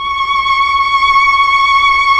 Index of /90_sSampleCDs/Keyboards of The 60's and 70's - CD1/STR_Melo.Violins/STR_Tron Violins
STR_TrnVlnC#6.wav